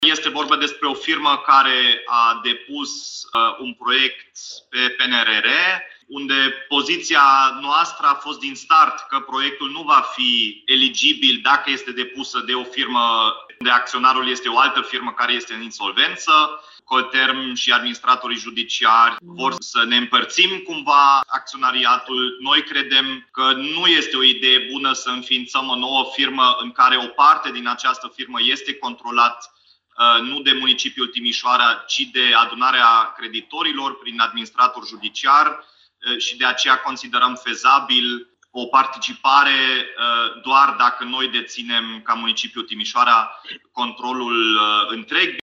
Modificarea aprobată de consilierii locali timișoreni este necesară pentru că doar astfel pot fi obținute finanțări din PNRR pentru retehnologizarea Colterm, explică primarul Dominic Fritz.